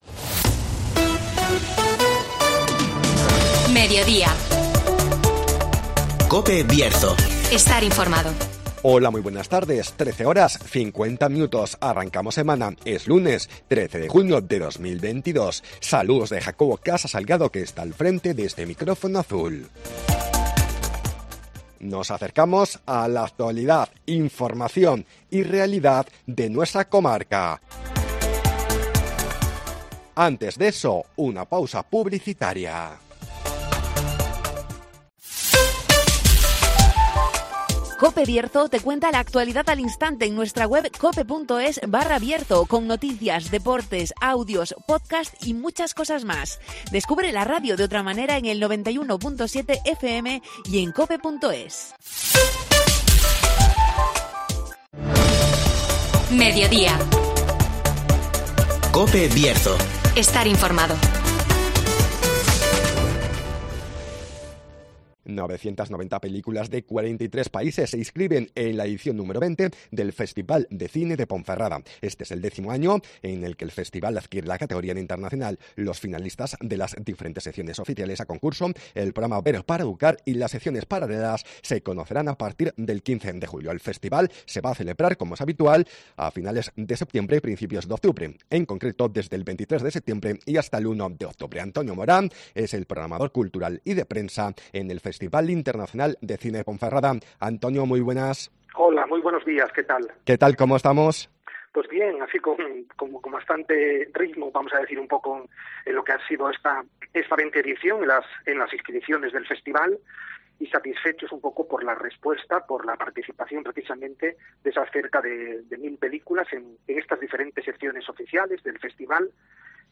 990 películas de 43 países se inscriben en la edición número 20 del Festival de Cine de Ponferrada (Entrevista